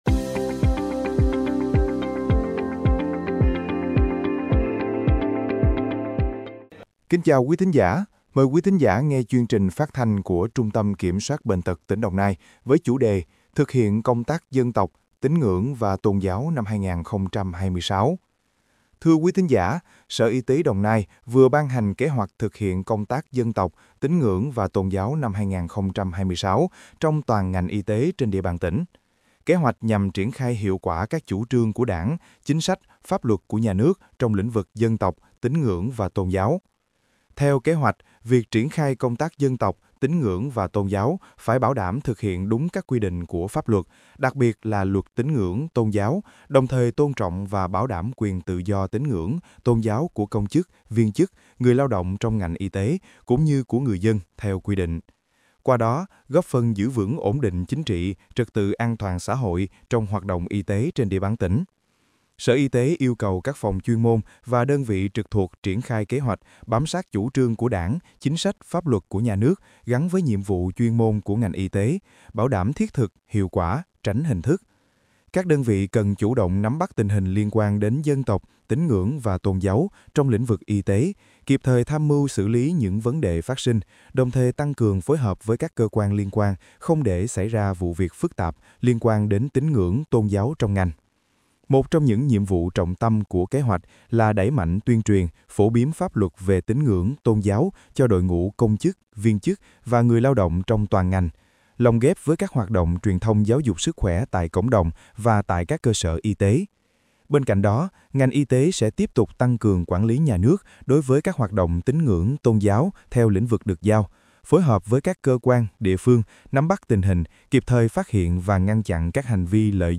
File phát thanh: Sở Y tế Đồng Nai ban hành kế hoạch công tác dân tộc, tín ngưỡng, tôn giáo năm 2026